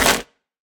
Minecraft Version Minecraft Version snapshot Latest Release | Latest Snapshot snapshot / assets / minecraft / sounds / block / mangrove_roots / break2.ogg Compare With Compare With Latest Release | Latest Snapshot
break2.ogg